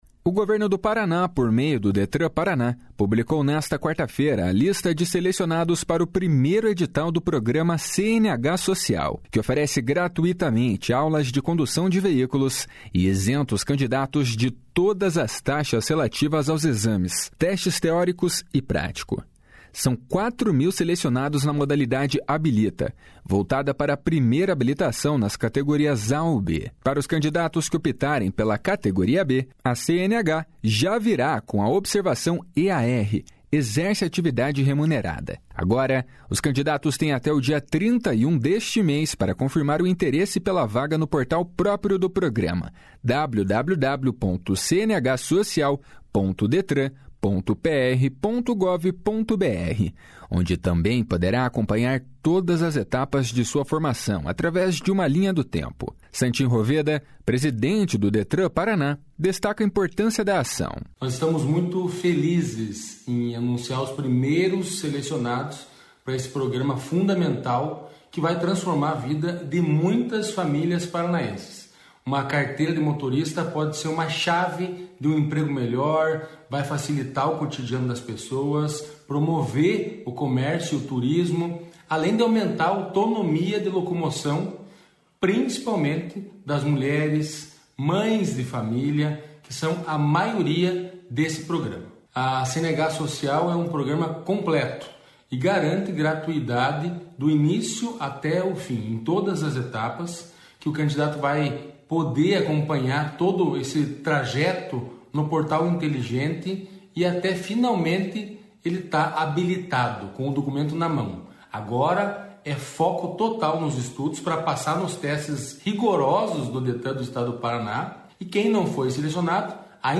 Santin Roveda, presidente do Detran-PR, destaca a importância da ação.